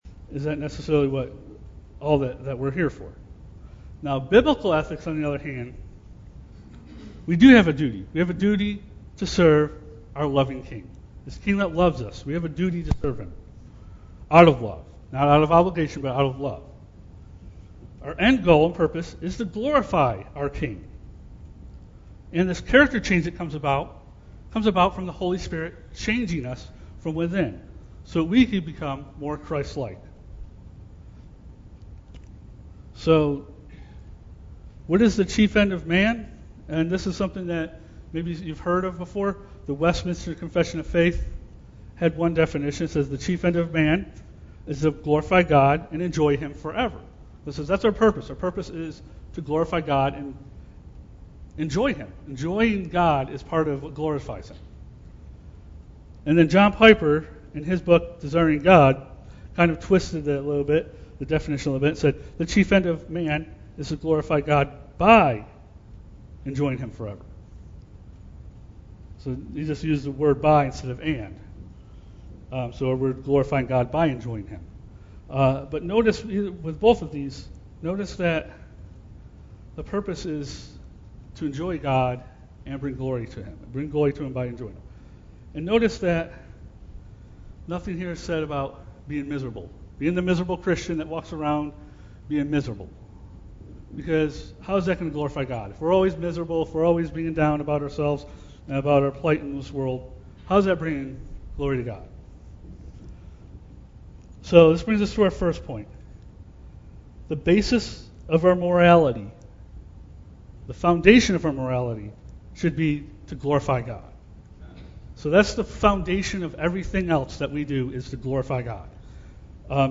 Due to technical issues, we were not able to get the entire sermon recorded. Part of the beginning is missing.